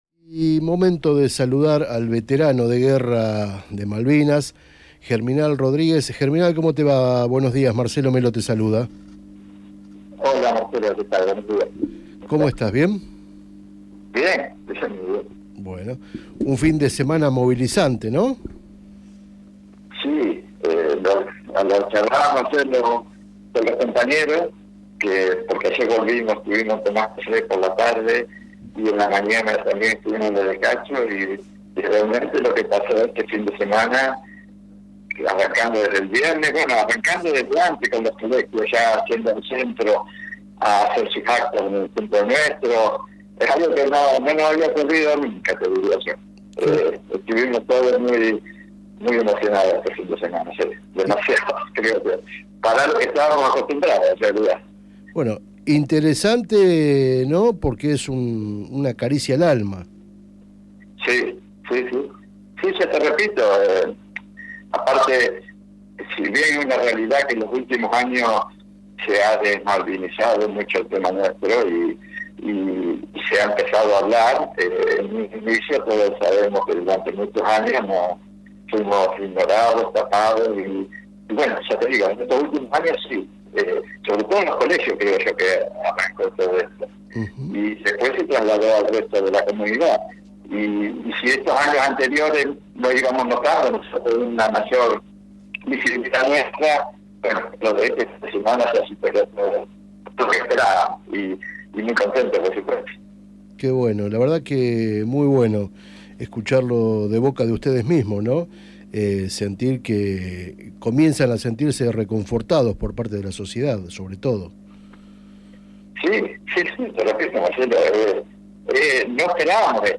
ENTREVISTA AL VETERANO DE GUERRA QUE LOGRO LA FOTO DE LAS NUBES CON FORMA DE MALVINAS - Dato Posta